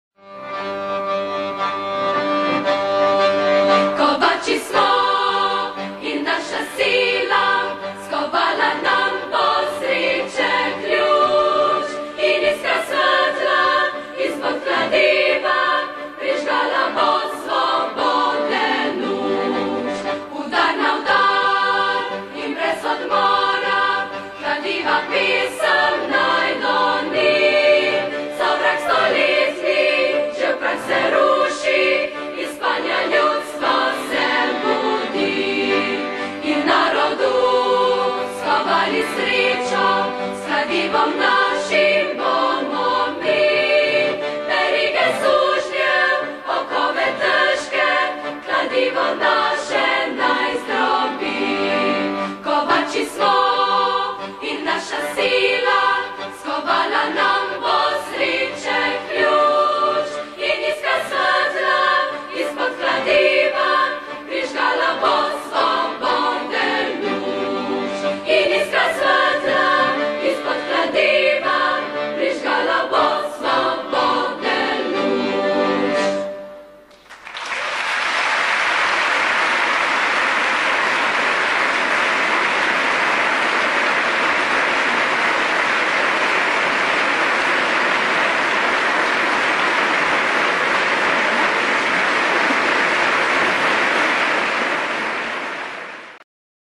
Революционная пролетарская песня